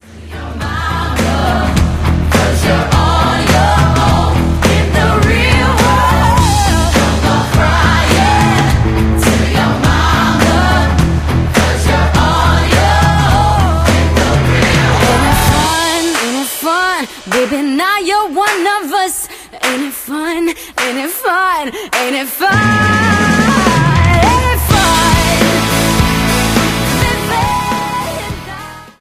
gospel choir